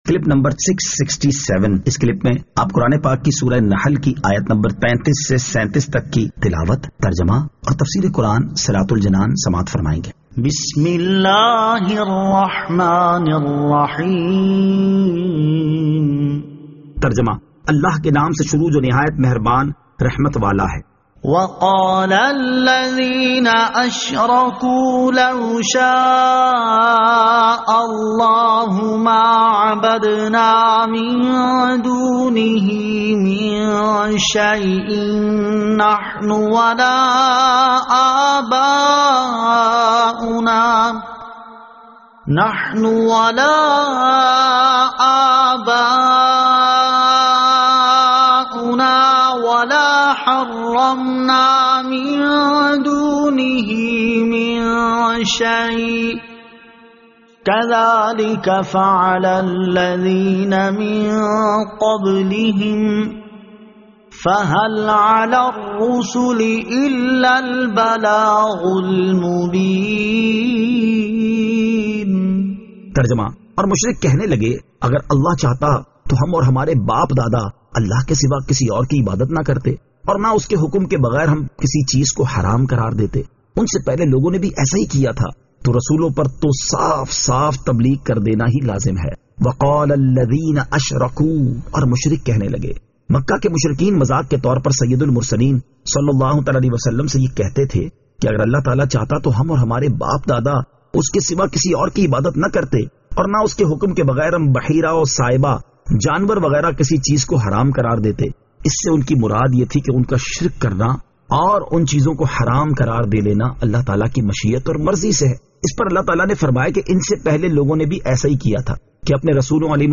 Surah An-Nahl Ayat 35 To 37 Tilawat , Tarjama , Tafseer
2021 MP3 MP4 MP4 Share سُوَّرۃُ النَّحٗل 35 تا 37 تلاوت ، ترجمہ ، تفسیر ۔